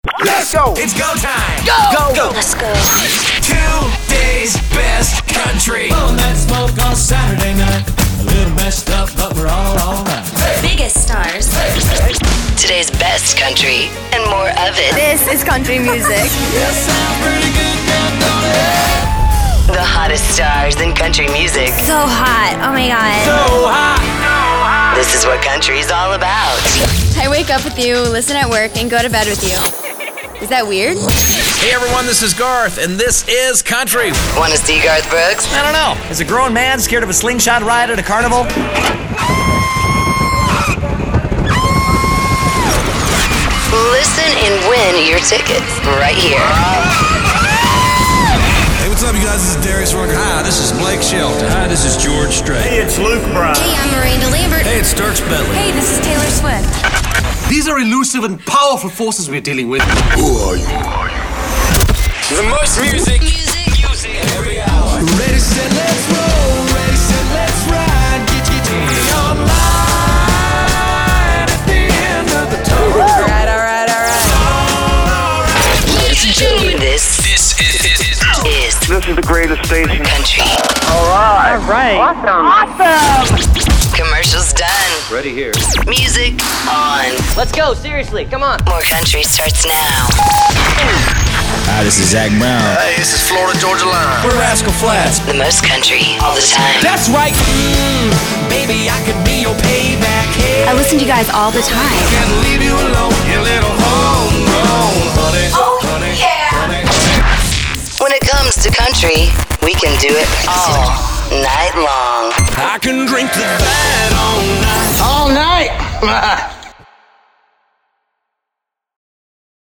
COUNTRY Hot
Country proud and turned up load!
Fresh, exciting sweepers, jingles, artist ID's and more that will target your audience and butter your biscuits!